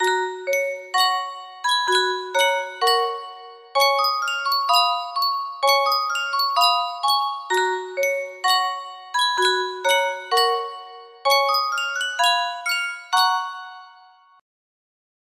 Sankyo Music Box - Hail Hail The Gang's All Here LTR music box melody
Full range 60